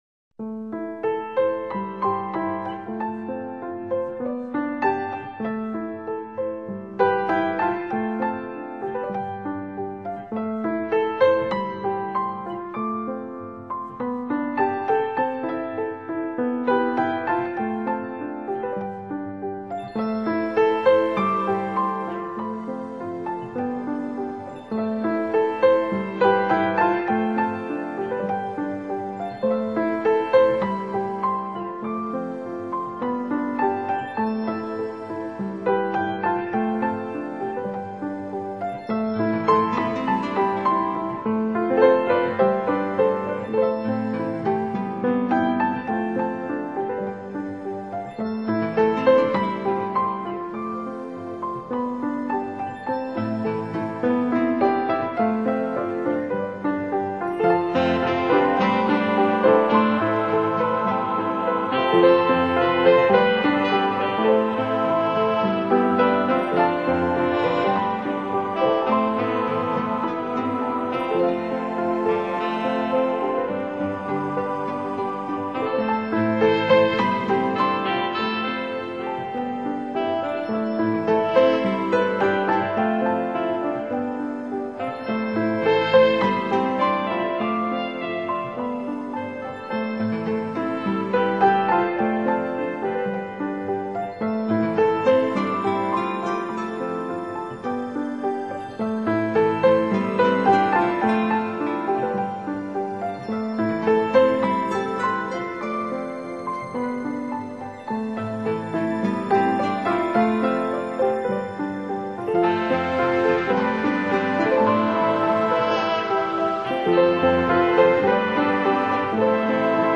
类型：NewAge
初听此专辑，没有很深的印象，平静，旋律平坦。
音乐的主题是慢慢的展开的，不紧不慢的，柔柔的，缓缓的，让我们感受到作者的情感，旋律的优美。